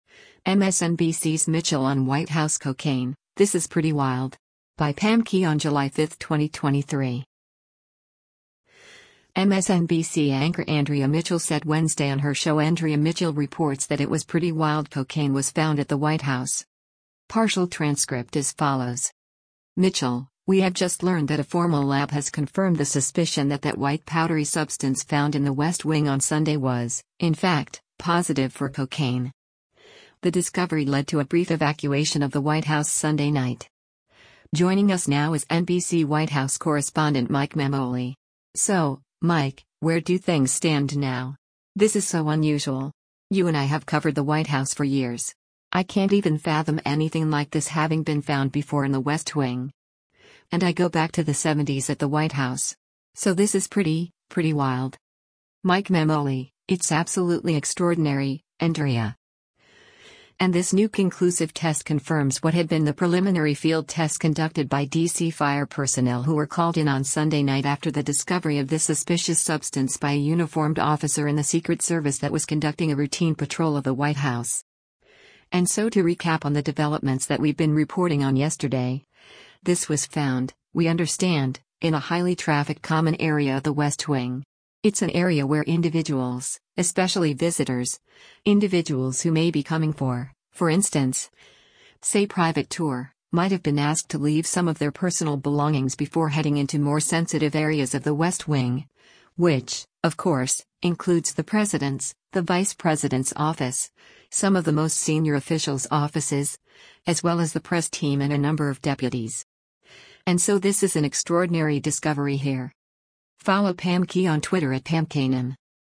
MSNBC anchor Andrea Mitchell said Wednesday on her show “Andrea Mitchell Reports” that it was “pretty wild” cocaine was found at the White House.